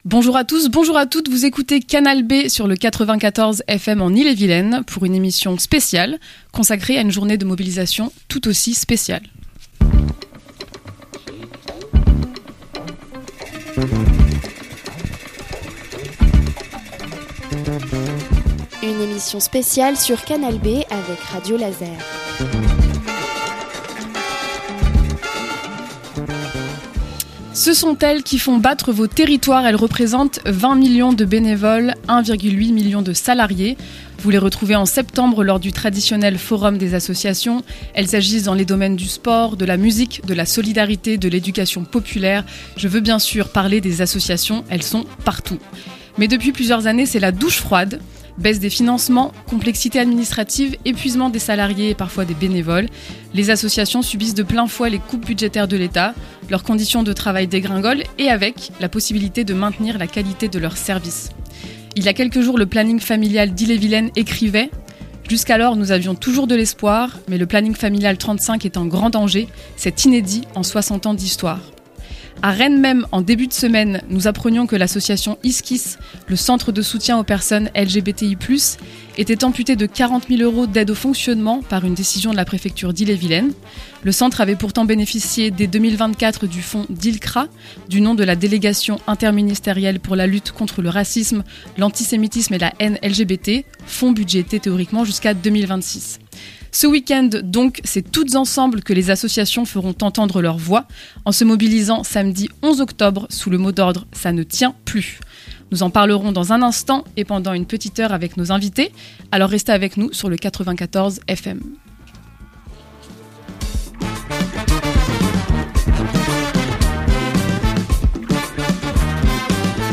Emission spéciale | "Ca ne tient plus !"